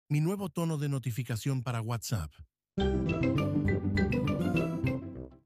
Tononde notificación para WhatsApp peppa sound effects free download